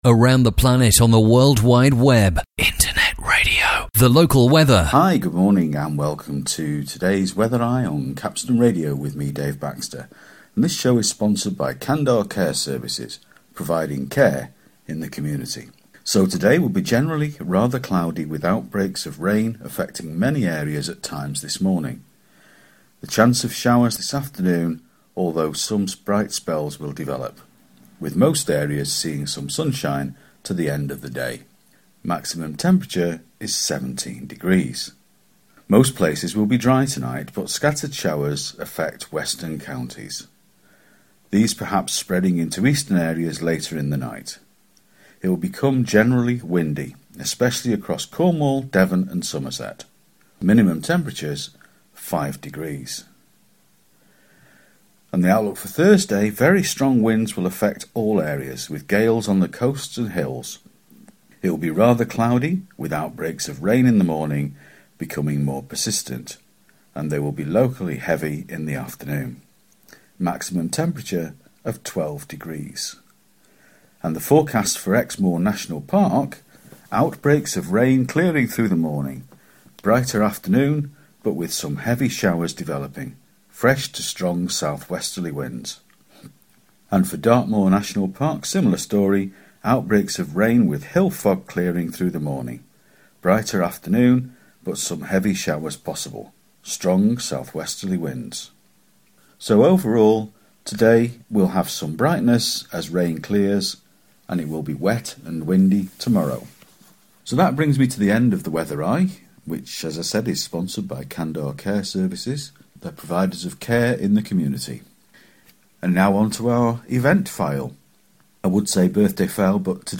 Capstone Radio's weather update, birthday file and wedding anniversary greetings